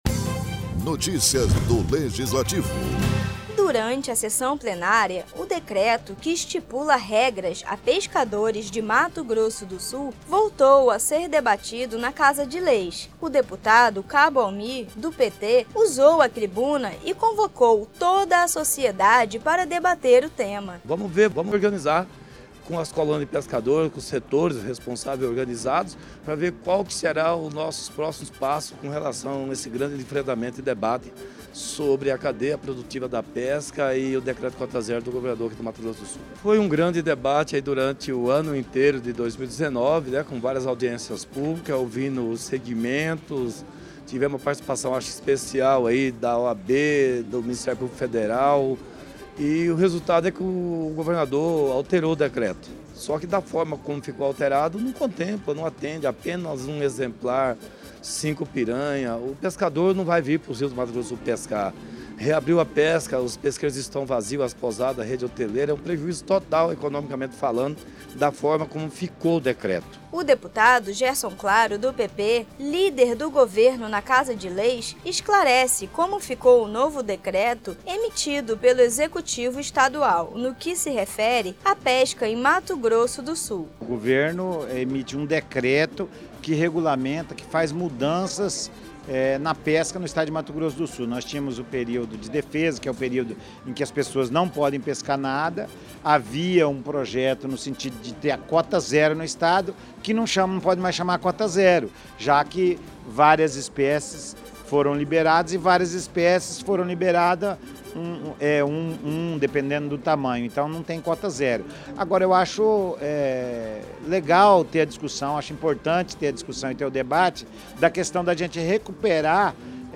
Deputado Cabo Almi, usou a tribuna para convidar a população a debater sobre o tema da Cota Zero, tema que envolve a pesca no Estado de Mato Grosso do sul.